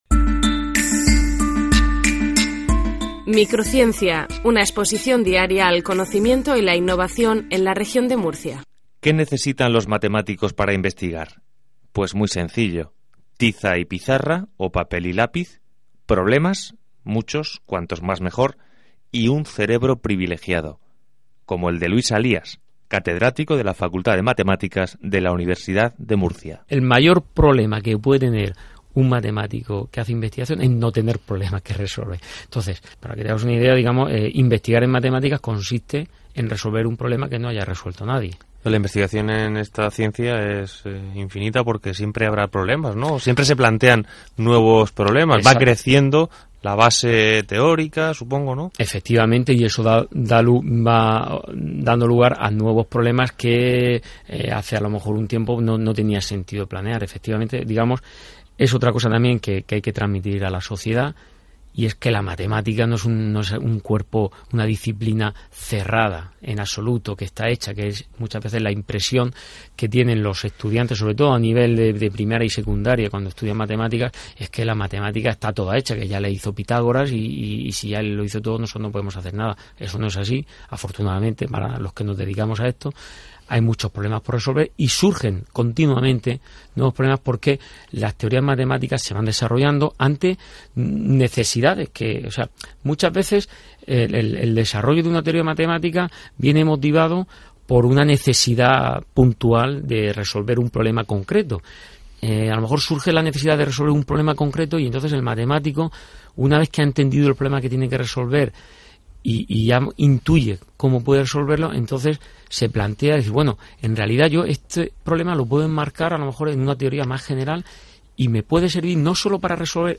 Dentro del Programa de Comunicación Pública de la Ciencia y la Tecnología, la Fundación Séneca patrocina el programa de radio "Microciencia", emitido a través de Onda Regional de Murcia.
Entrevista